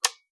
clock.wav